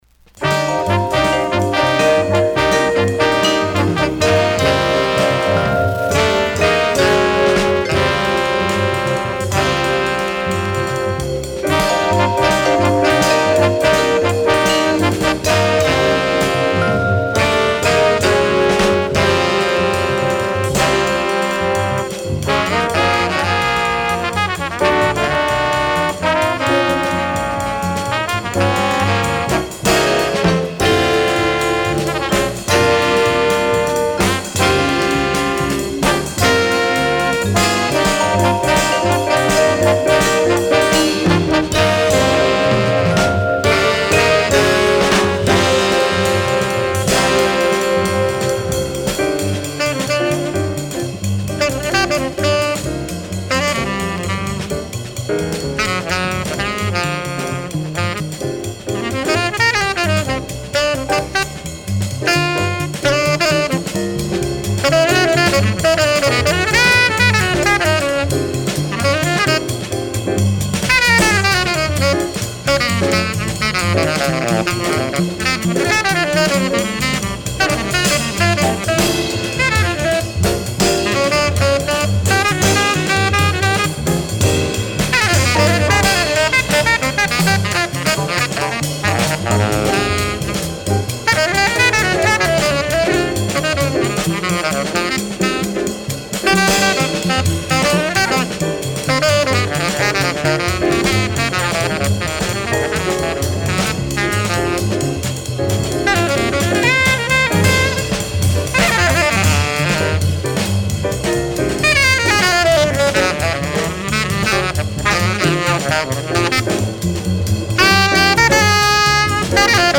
Second mono pressing ca. 1966-67